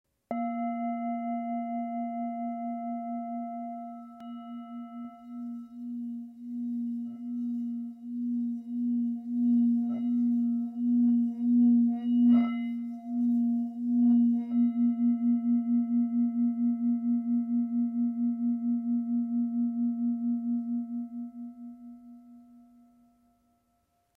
Tibetská mísa Gyal střední
Tepaná tibetská mísa Gyal o hmotnosti 934 g. Mísa je včetně paličky s kůží!
Lahodné dlouhotrvající tóny tibetské mísy nám umožňují koncentrovat naši mysl, relaxovat a uvolnit naše tělo.
tibetska_misa_s37.mp3